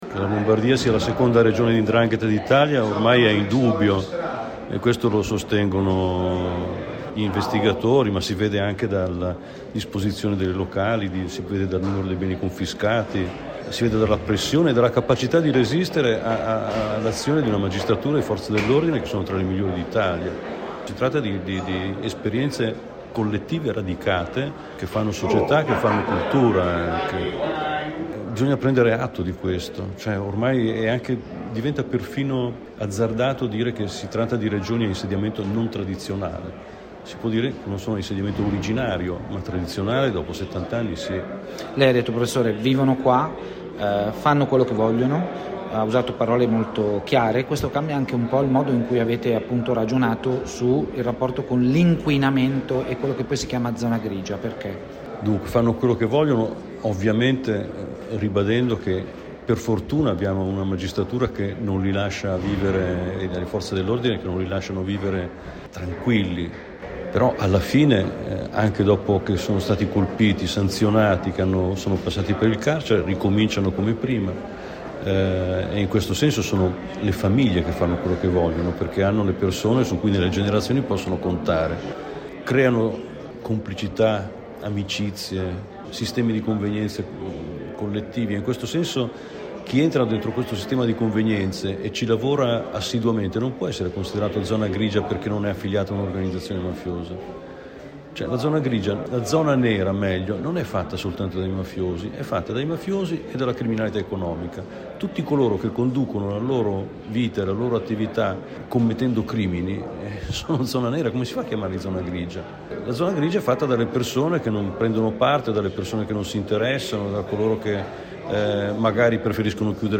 L’intervista integrale a Nando Dalla Chiesa